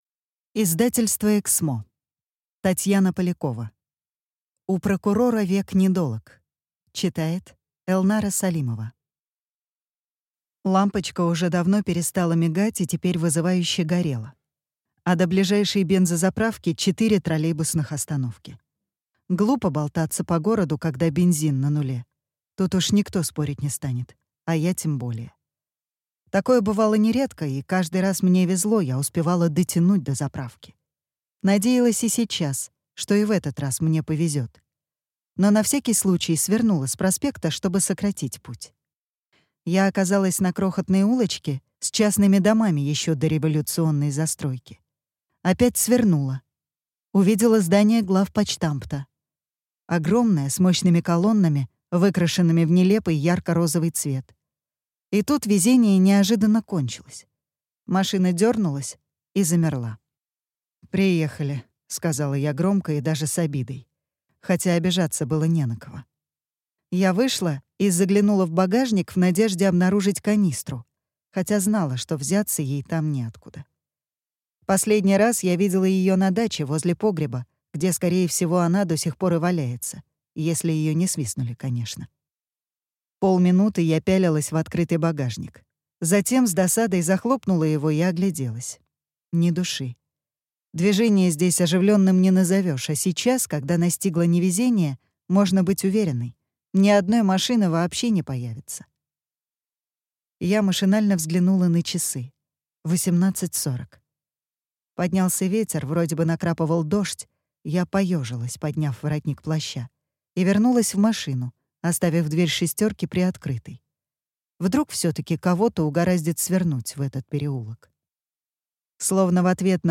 Аудиокнига У прокурора век недолог | Библиотека аудиокниг